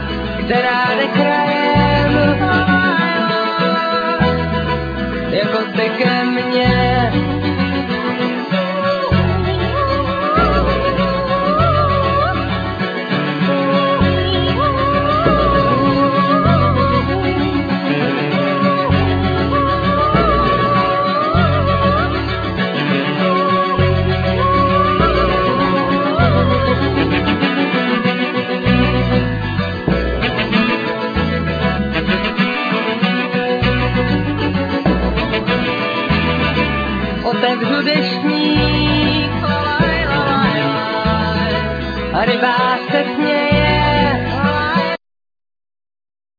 Vocal
Mandolin,Guitar
Saxophone,Clarinet,Keyboards,Whistle,Okarina
Cello,Violin,Bass